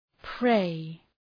Προφορά
{preı}